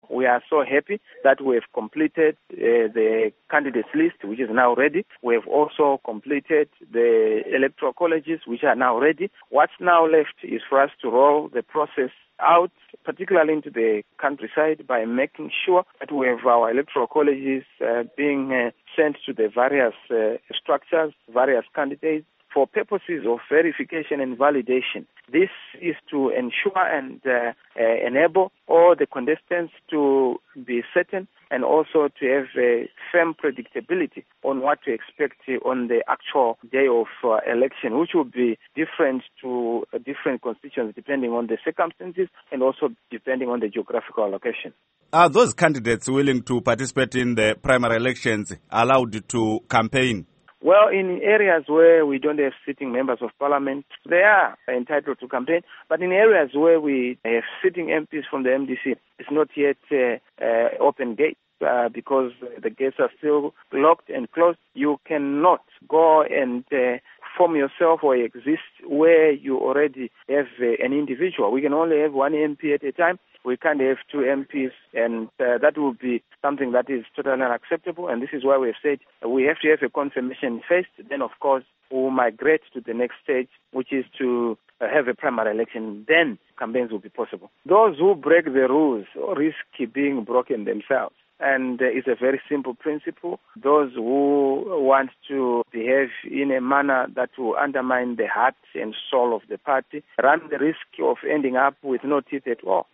Interview With Nelson Chamisa